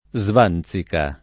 svanzica [ @ v # n Z ika ] s. f.